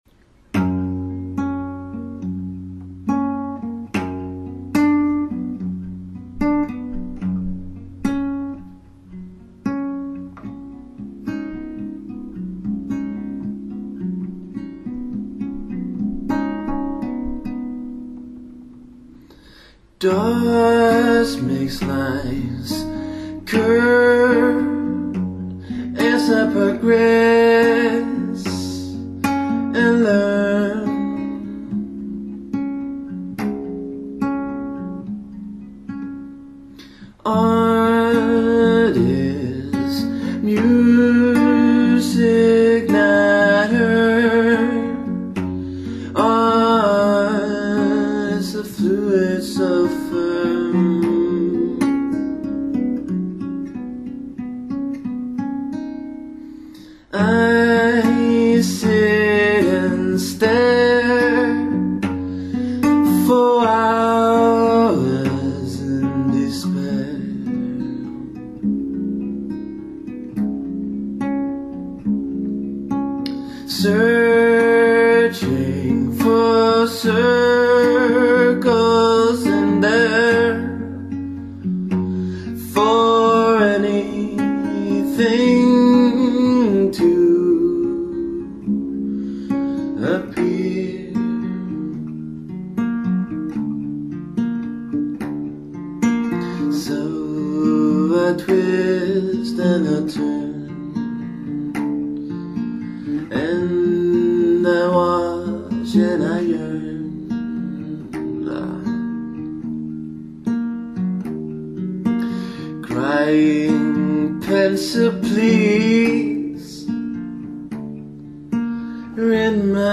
Inget brus och stort frekvensomfång.